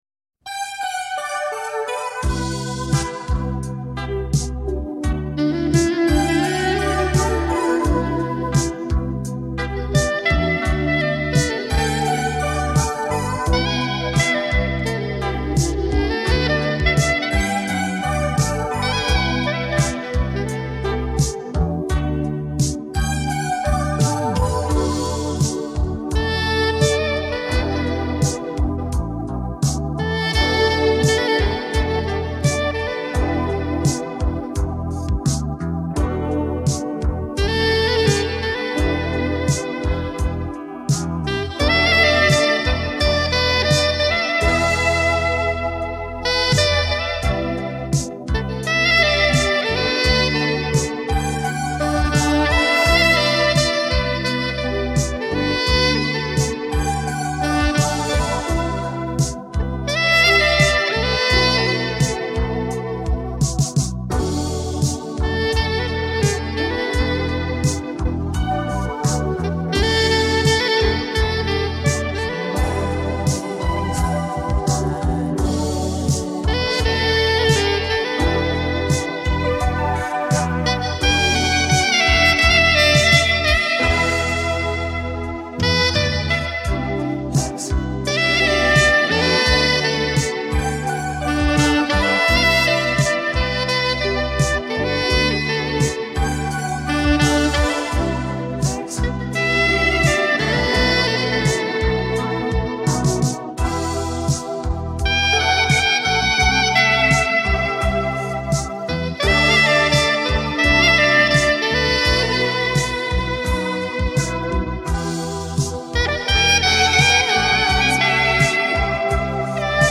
Instrumental Sax